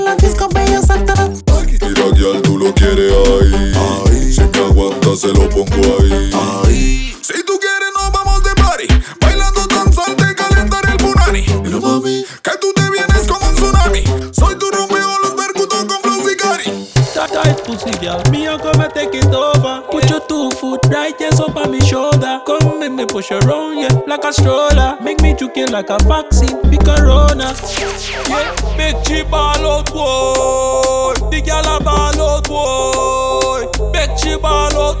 Жанр: Танцевальная музыка
# Modern Dancehall